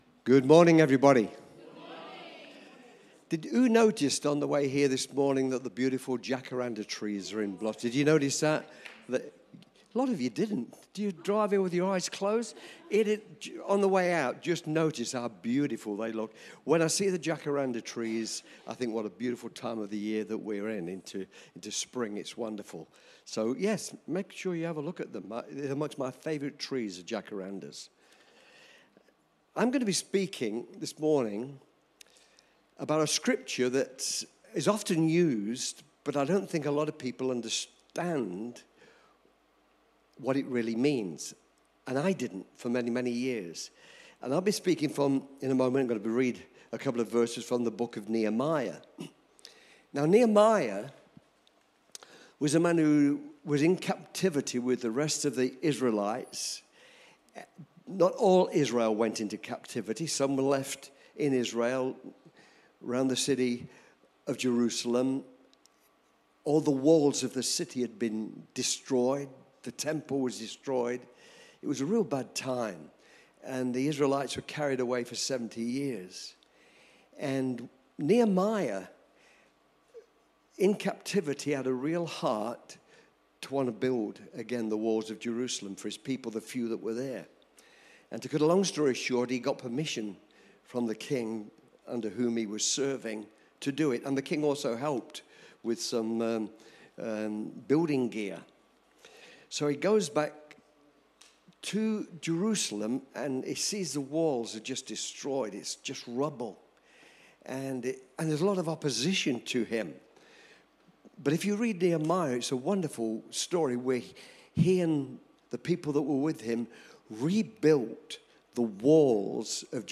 Preached at Cityview Church on Sunday 3rd November 2024.